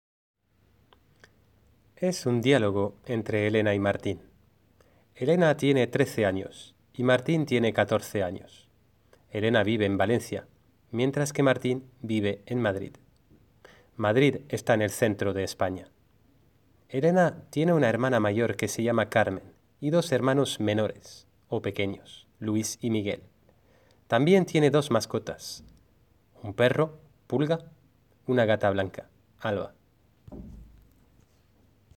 Lectura 5eLectura 5e - (del profesor)
lectura-5e.mp3